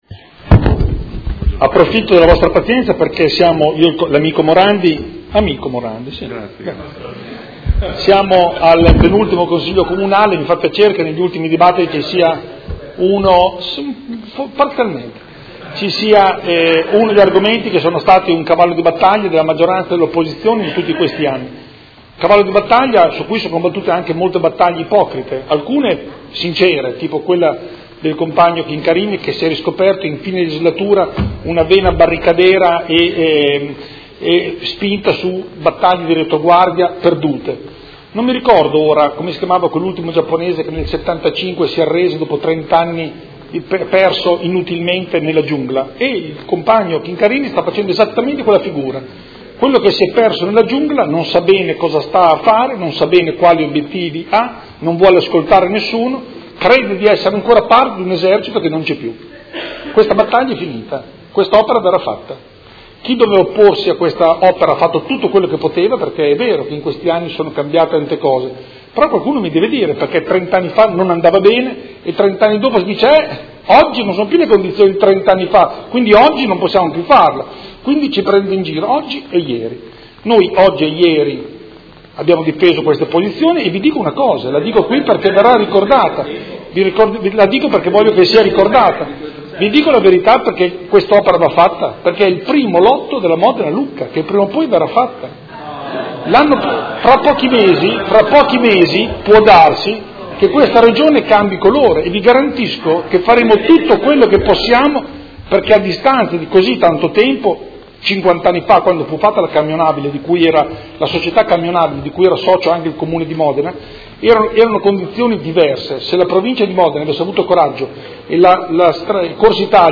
Andrea Galli — Sito Audio Consiglio Comunale
Seduta del 28/03/2019. Dibattito su Ordine del Giorno presentato dai Consiglieri Stella (SUM), dal Consigliere Chincarini (Modena Volta Pagina) e dal Consigliere Bussetti (M5S) avente per oggetto: Bretella autostradale Sassuolo- Campogalliano: opera inutile e dannosa per il nostro ambiente: finanziare la manutenzione della viabilità esistente e sviluppare il potenziamento e la qualificazione delle tratte ferroviarie Sassuolo- Modena e Sassuolo-Reggio, ed emendamento; Ordine del Giorno Prot.